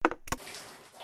Milk Carton Spill Sound made by dropping a gallon of Milk full of water in my bath tub and then punching said milk jug
milk_carton_spill_0.mp3